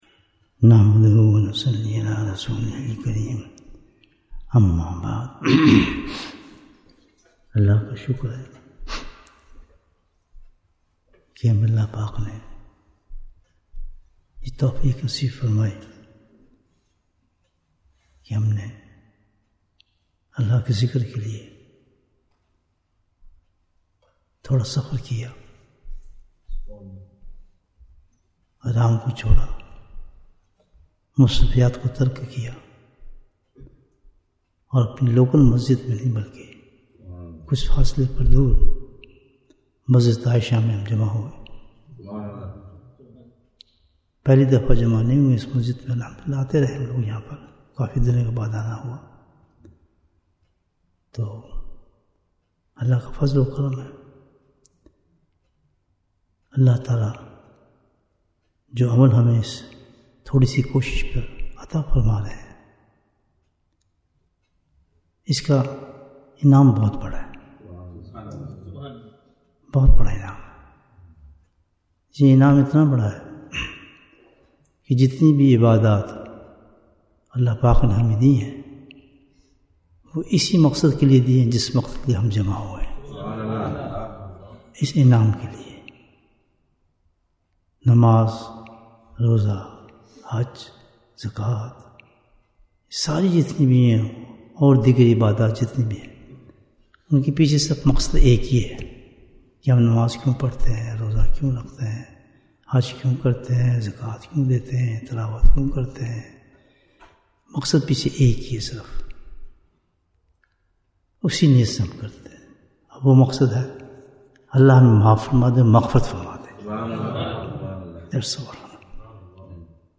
مغفرت کا دروازہ Bayan, 24 minutes23rd November, 2024